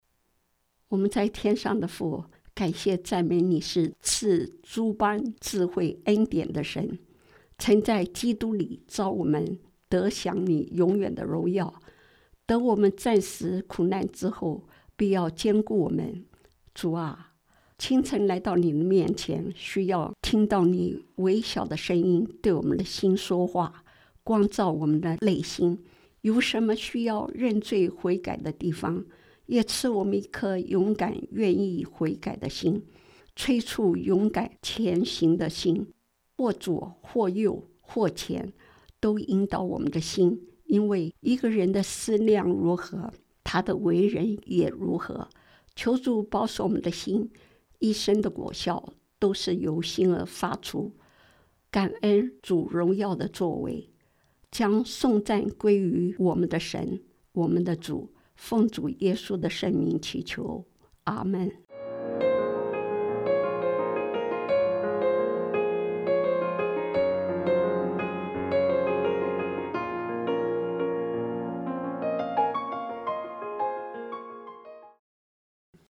今日祈祷– 勇敢愿意悔改的心